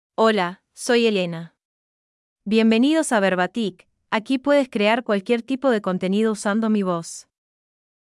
FemaleSpanish (Argentina)
Elena is a female AI voice for Spanish (Argentina).
Voice sample
Elena delivers clear pronunciation with authentic Argentina Spanish intonation, making your content sound professionally produced.